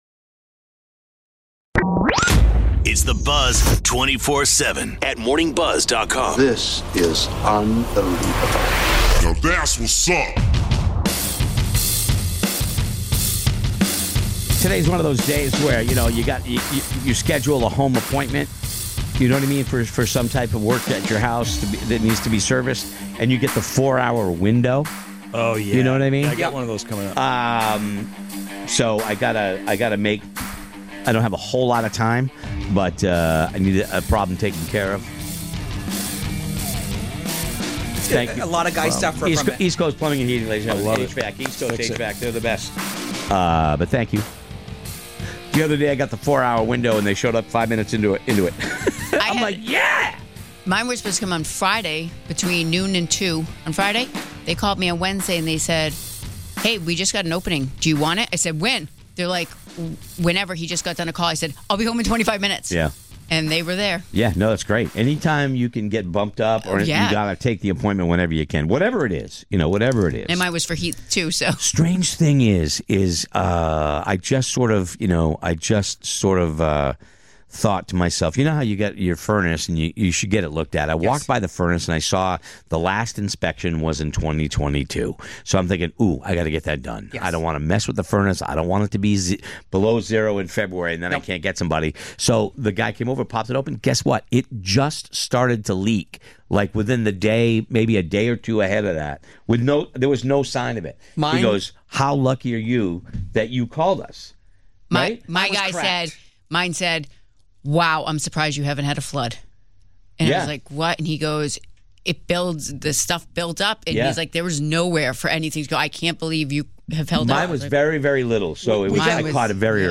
Talk Radio